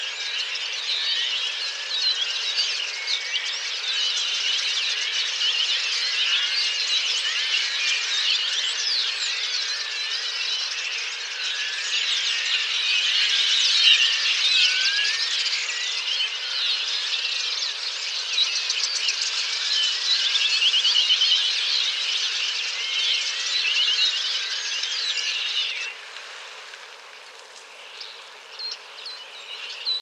etourneau-unicolore.mp3